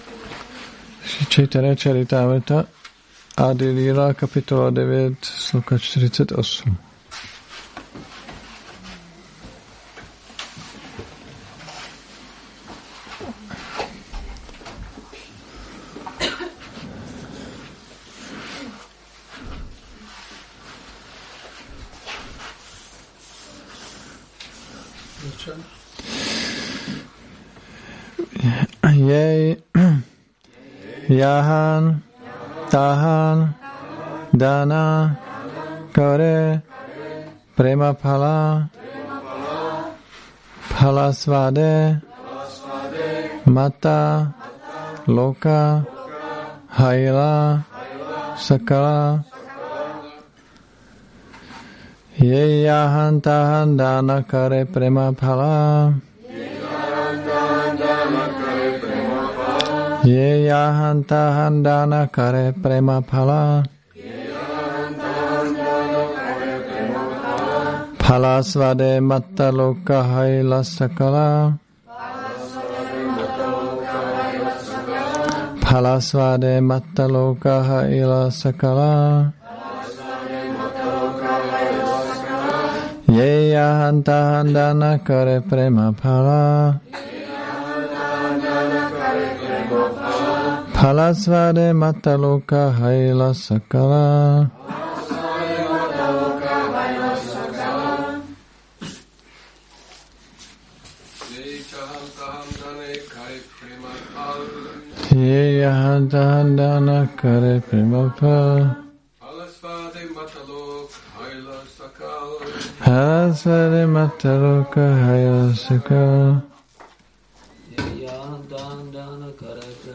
Šrí Šrí Nitái Navadvípačandra mandir
Přednáška CC-ADI-9.48